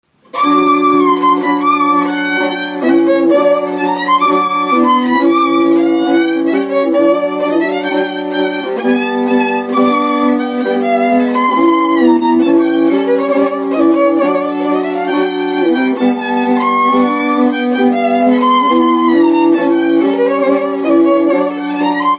Dallampélda: Hangszeres felvétel
Felföld - Nógrád vm. - Nagybárkány
hegedű
brácsa Gyűjtő